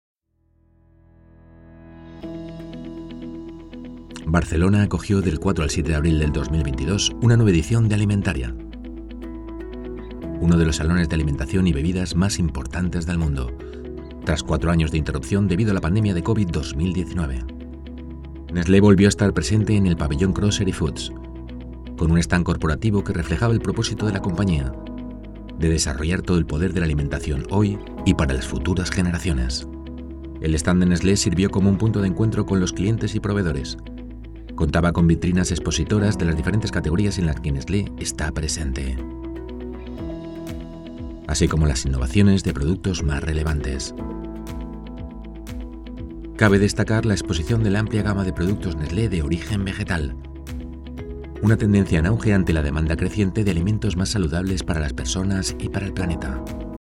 Dokumentarfilme
Voz neutral, emotiva, energética y divertida
Profesional Studio at home